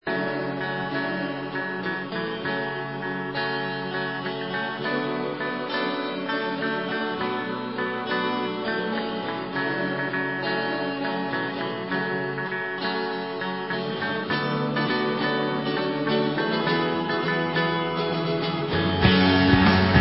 sledovat novinky v kategorii Rock